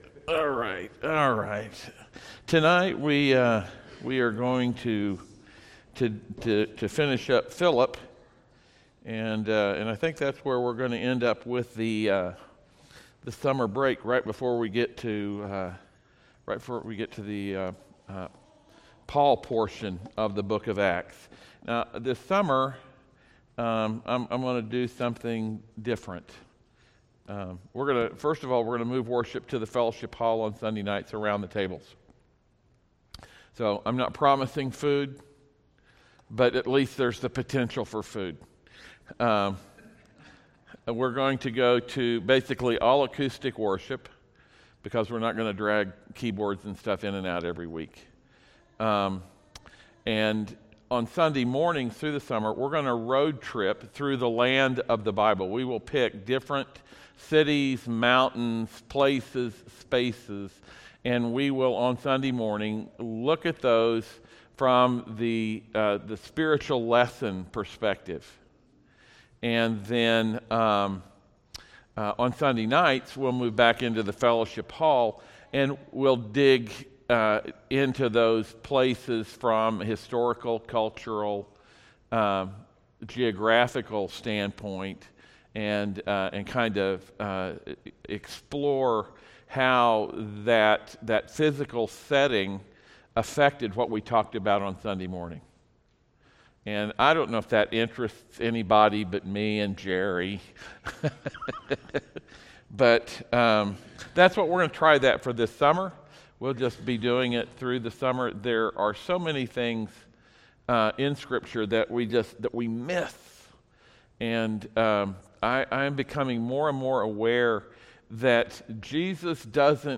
Service Type: audio sermons
Sermon-Audio-for-May-5th-PM.mp3